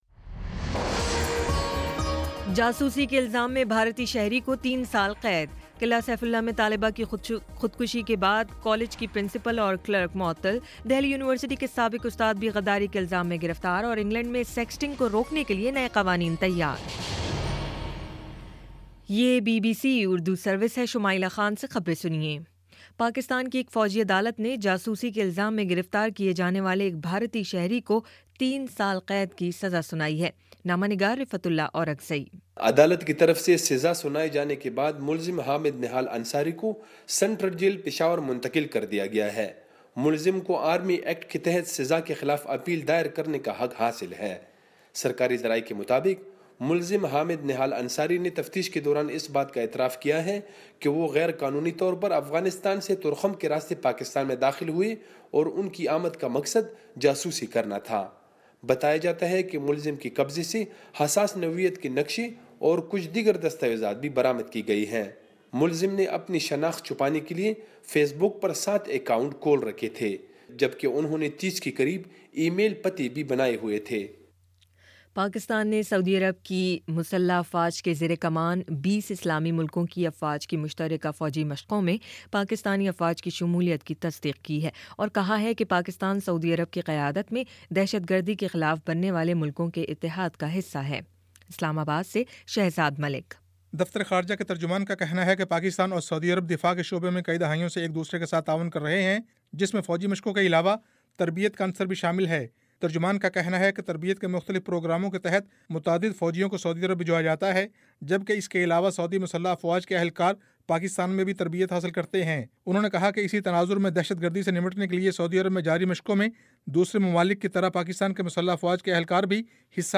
فروری 16 : شام پانچ بجے کا نیوز بُلیٹن